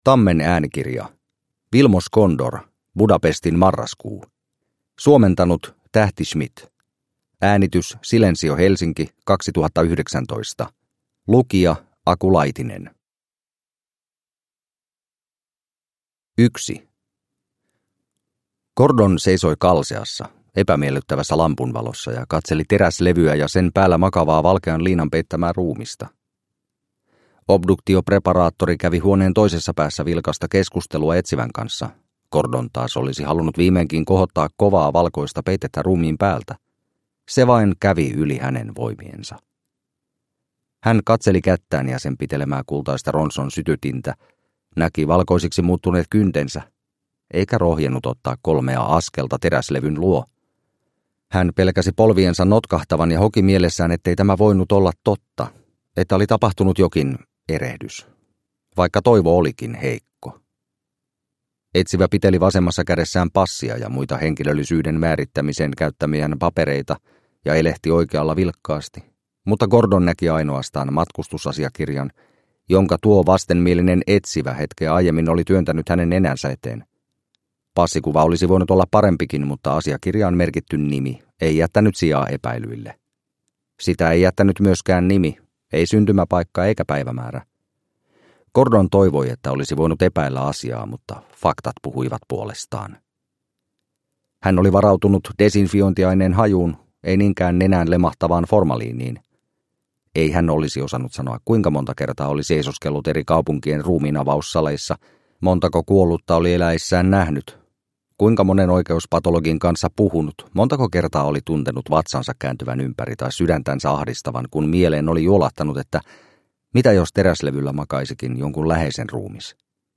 Budapestin marraskuu – Ljudbok – Laddas ner